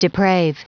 Prononciation du mot deprave en anglais (fichier audio)
Prononciation du mot : deprave